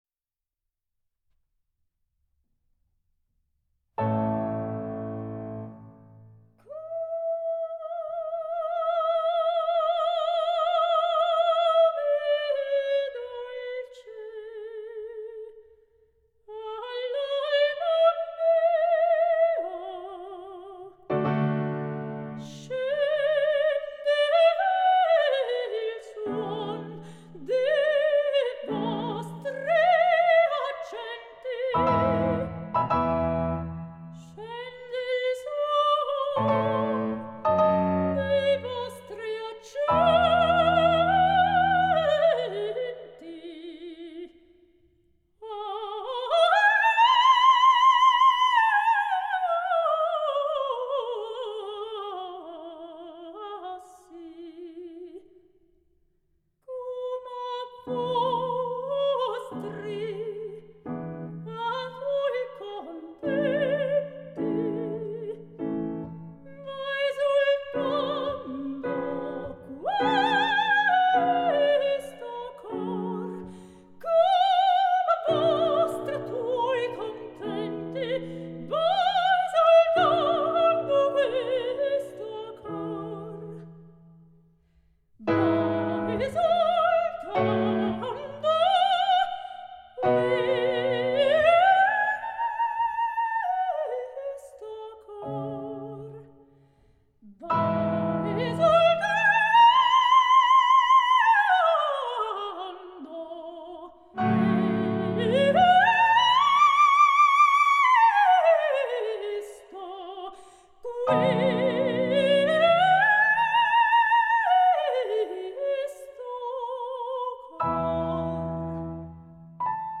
Soprano
Pianoforte